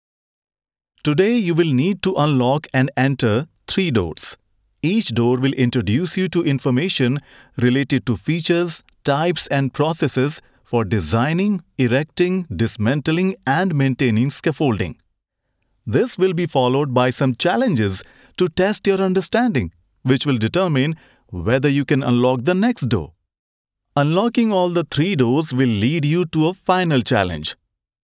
Asian, Hindi. Presenter, Warm, friendly, believable.
Male Hindi Voice Over